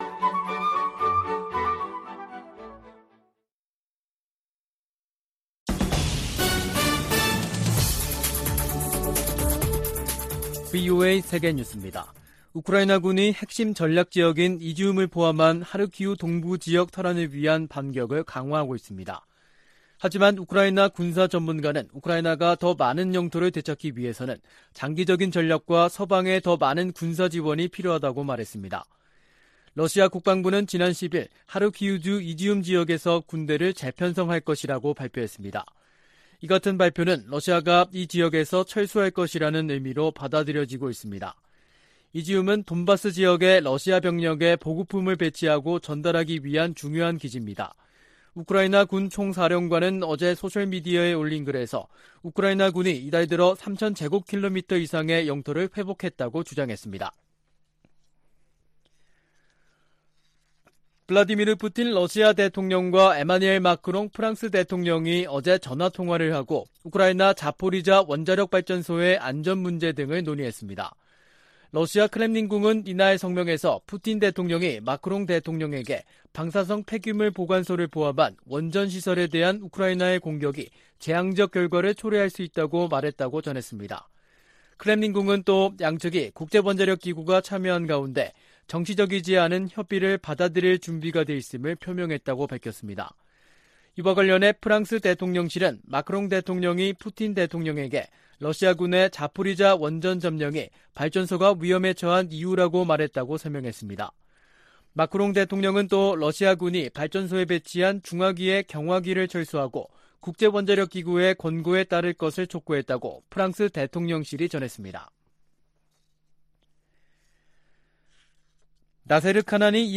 VOA 한국어 간판 뉴스 프로그램 '뉴스 투데이', 2022년 9월 12일 2부 방송입니다. 북한이 경제난 속 ‘핵 법제화’를 강행하는 것은 중러와의 3각 밀착이 뒷받침을 하고 있다는 분석이 나오고 있습니다.